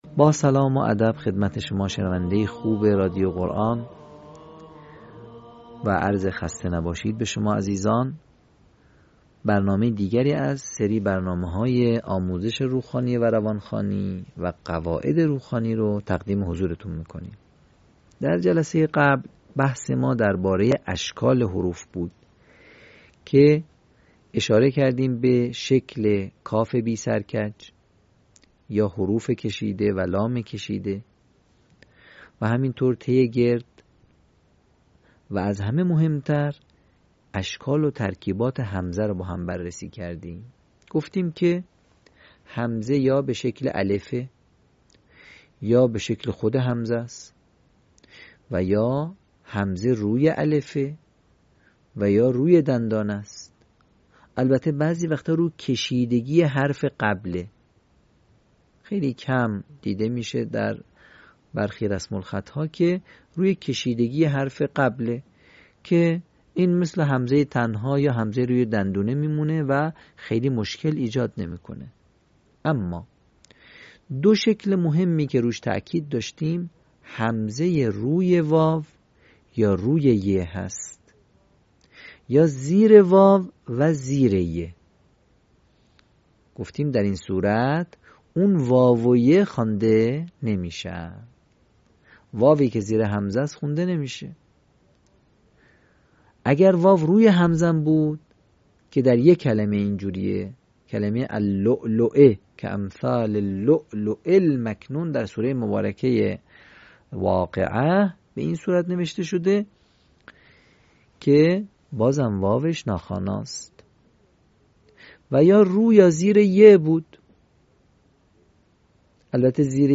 صوت | آموزش روخوانی «ترکیب همزه و صداهای کشیده»